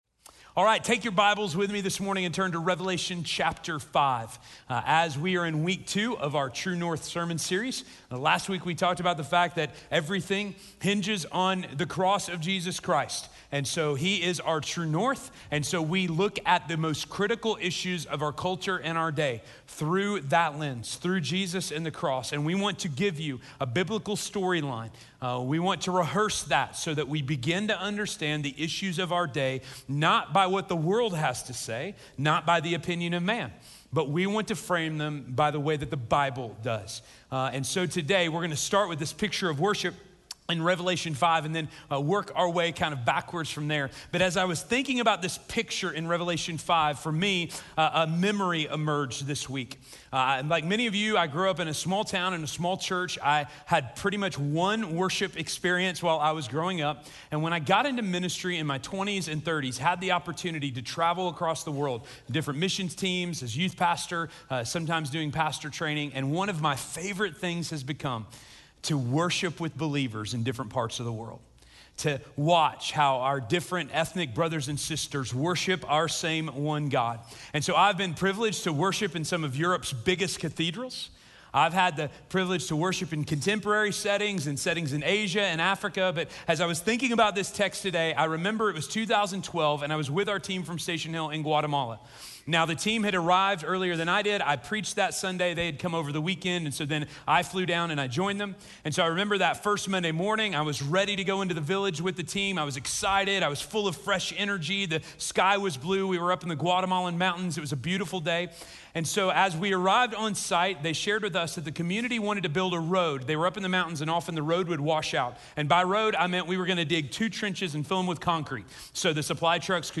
The world continues to lose its way regarding race relations. By looking at how everything will unfold in the end, this sermon will challenge our people with the reality that the Gospel is for all people – bringing all of us (red and yellow, black and white) into God’s eternal family.